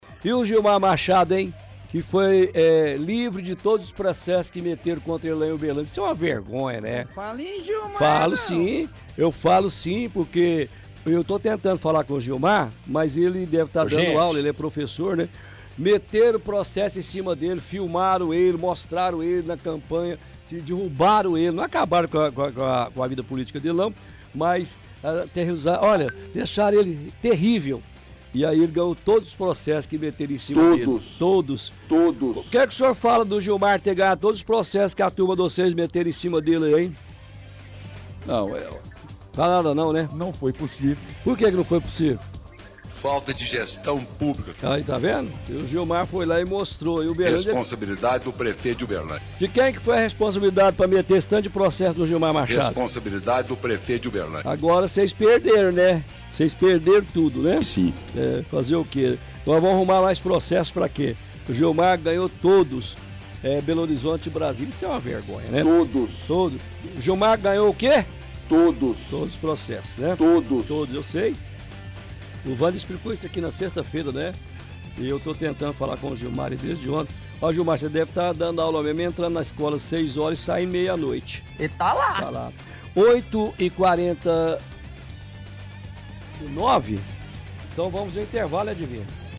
– Utiliza vários áudios do prefeito.